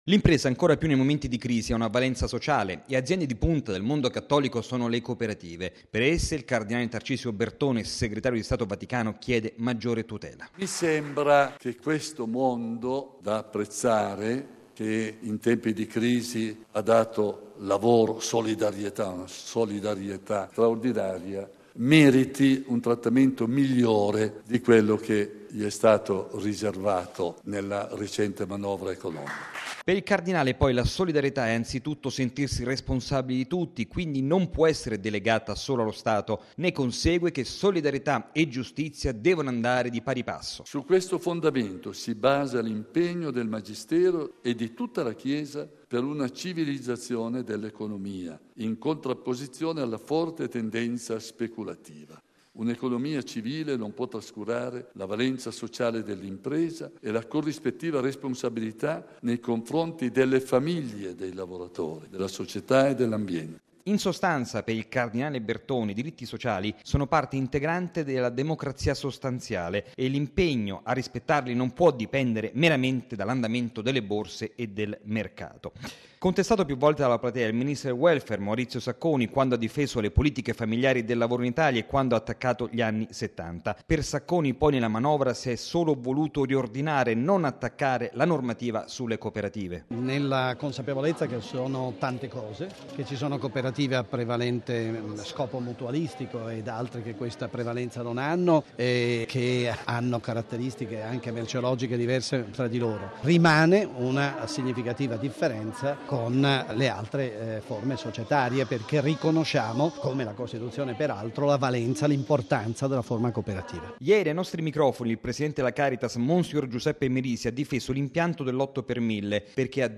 Contestato più volte dalla platea il ministro del Welfare, Maurizio Sacconi, quando ha difeso le politiche familiari e del lavoro in Italia e quando ha attaccato gli anni ’70.